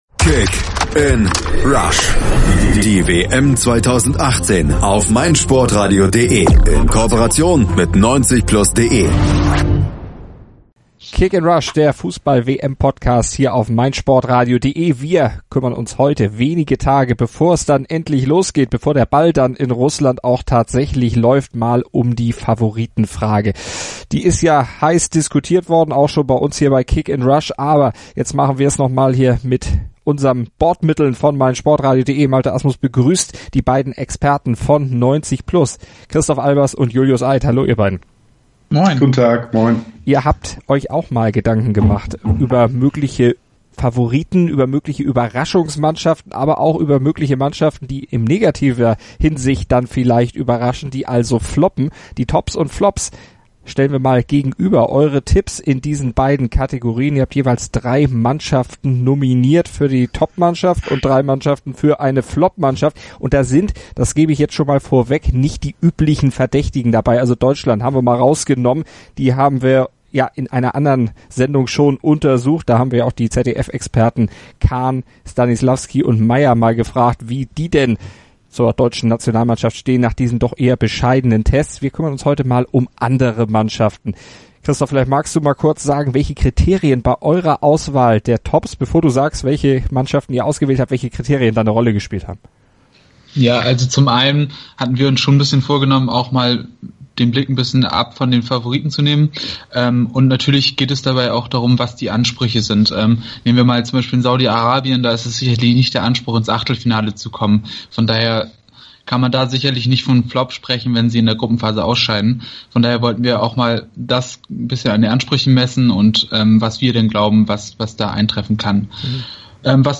Die beiden Experten für internationalen Fußball haben jeweils drei Kandidaten für positive und drei für negative Schlagzeilen im Gepäck und begründen ihre Wahl. Und dabei liegen sie einige Male über Kreuz.